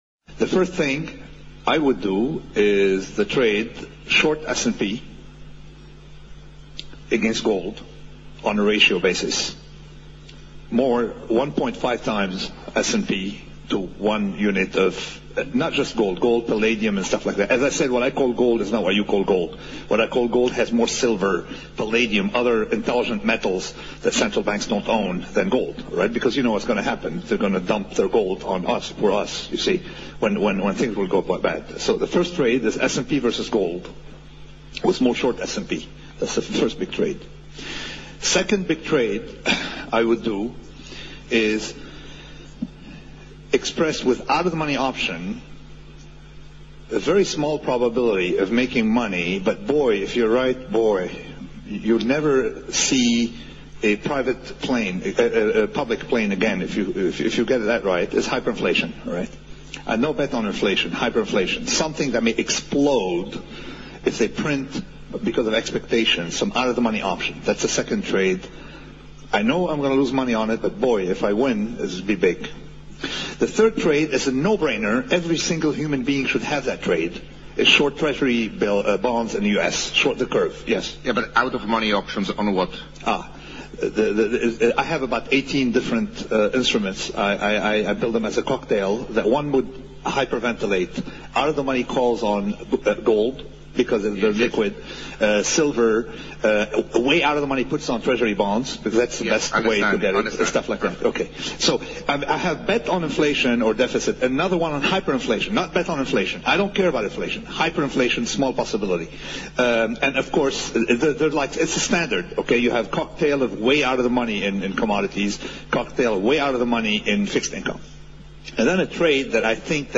About the closest I’ve heard NNT come to actually defining his (hedge for hyperinflation) investment formula was at the Moscow Forum in February 2010.